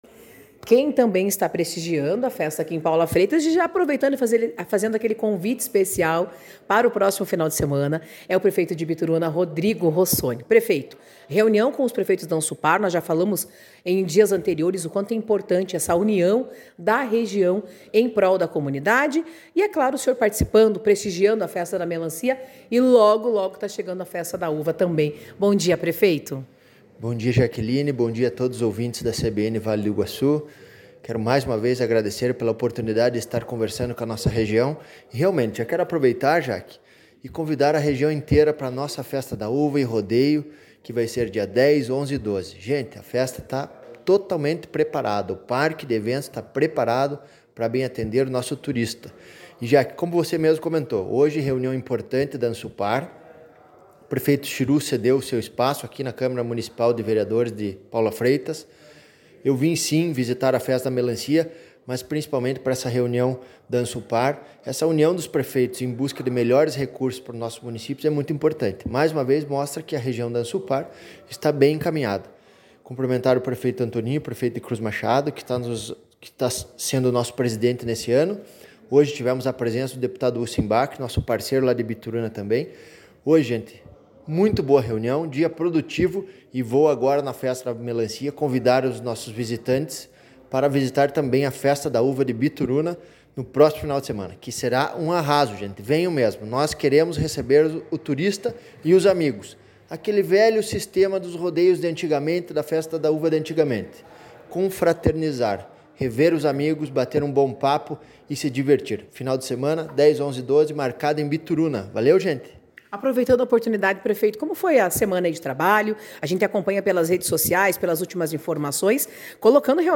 Prefeito de Bituruna, Rodrigo Rossoni